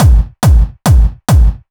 VTDS2 Song Kit 08 FX Vocal Candy Guy Kick.wav